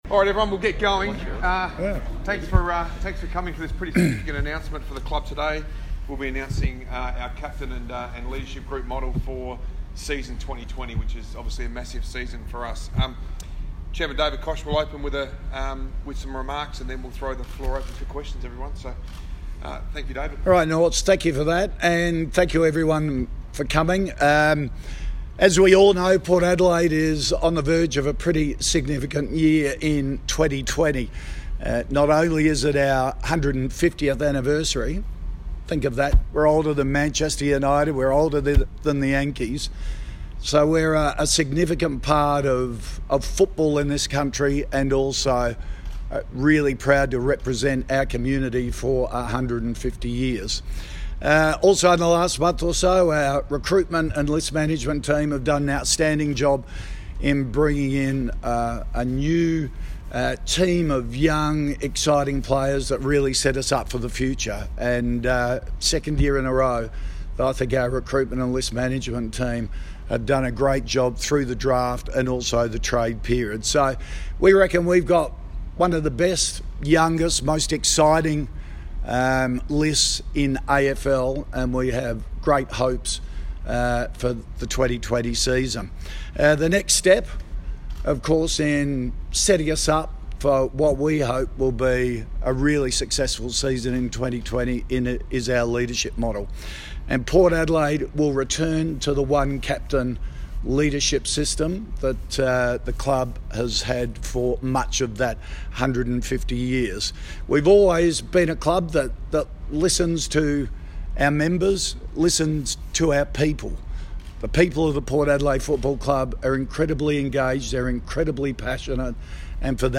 Captaincy press conference - Friday 6 December
David Koch, Ken Hinkley, Tom Jonas and Ollie Wines speak to the media as Port Adelaide's leadership structure is announced for 2020.